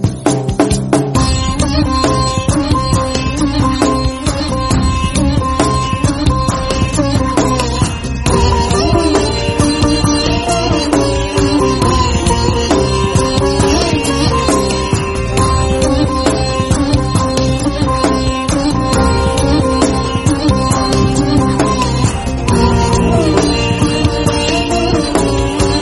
Devotional Ringtones
Instrumental Ringtones